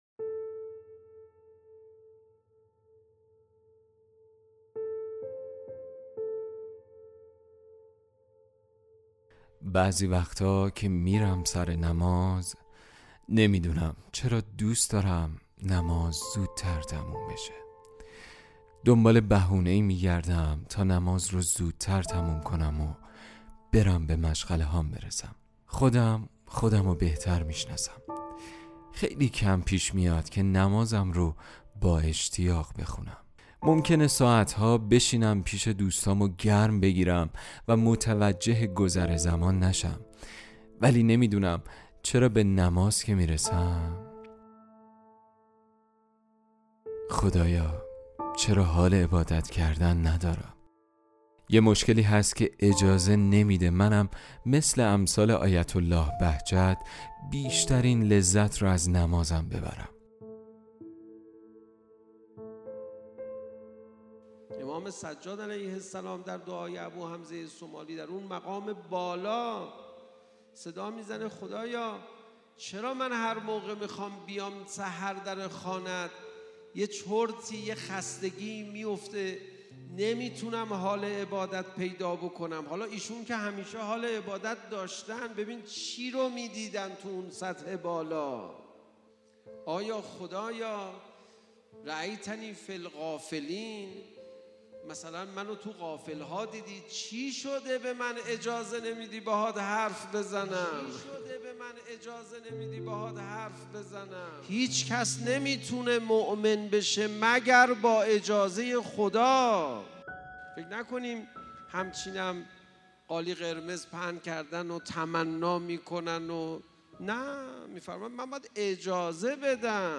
کلیپ تصویری | اجازه عاشقی سخنرانی علیرضا پناهیان در پاسخ به این سوال: چرا گاهی اوقات حال عبادت نداریم؟